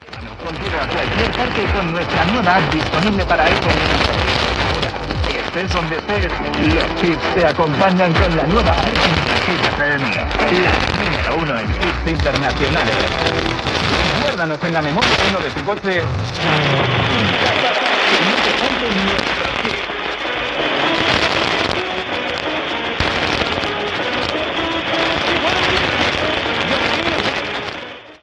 Anunci de l'aplicació de Hit FM
Qualitat de so defectusosa.